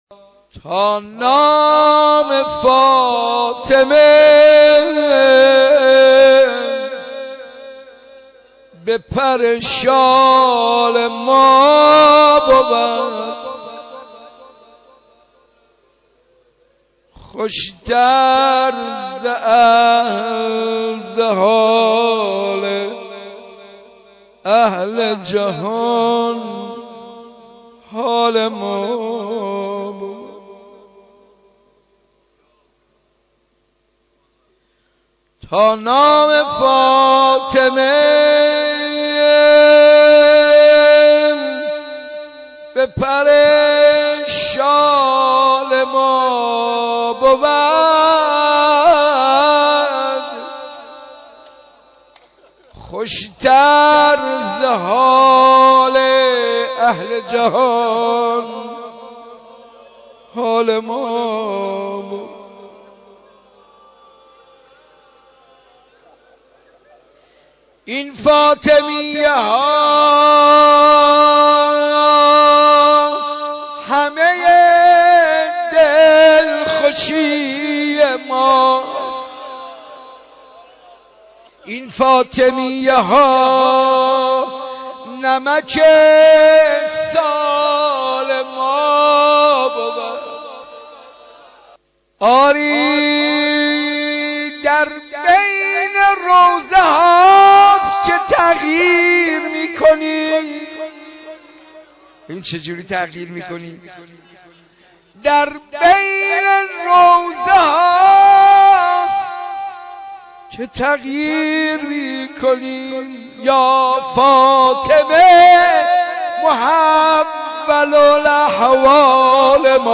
شب دوم فاطمیه 91 مسجد ارک